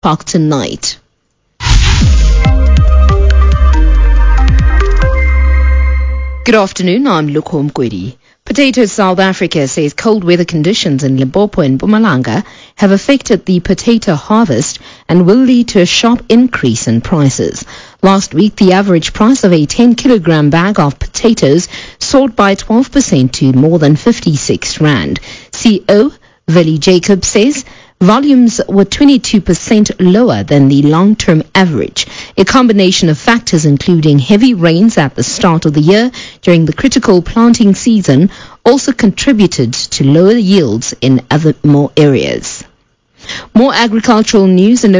To listen to the Impact Radio (news clip), as broadcasted on 23 June 2021 at 15:45, click here.